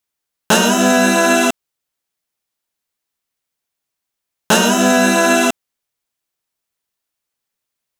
Astro 4 Vox-E.wav